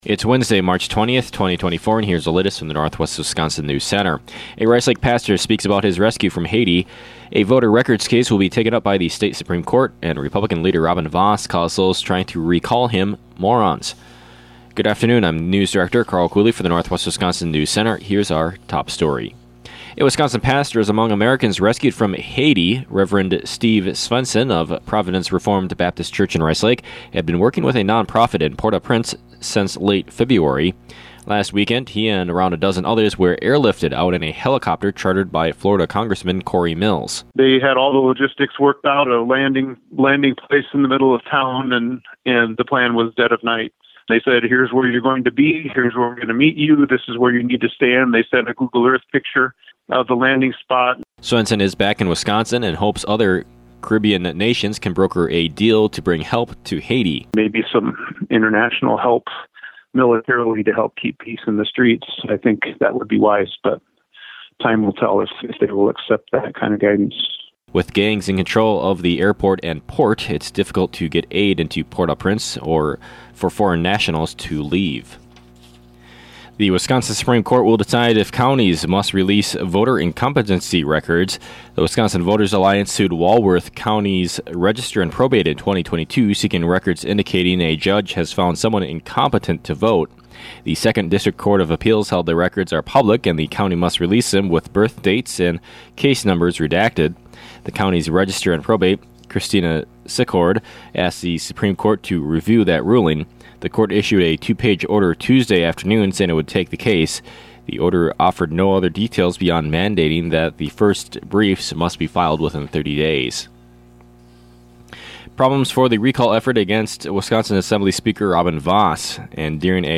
PM NEWSCAST – Wednesday, March 20, 2024